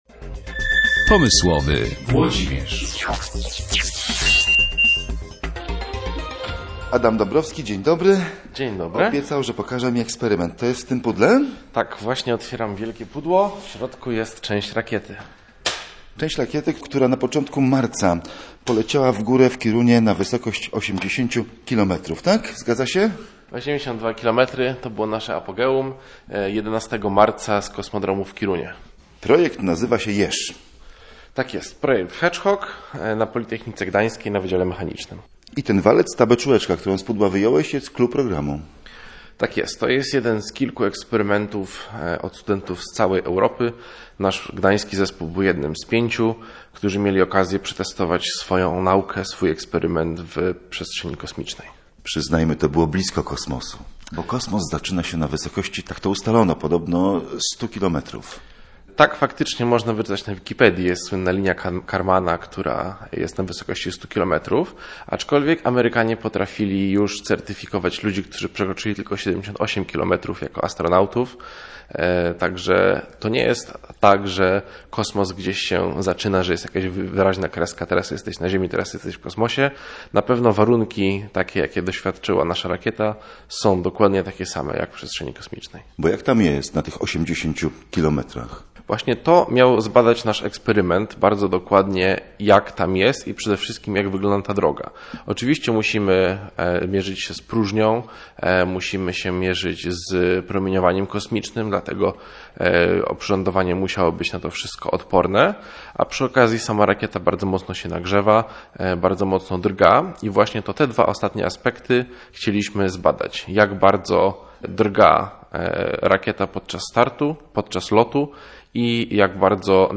Wysłali w kosmos „jeża” [ROZMOWA]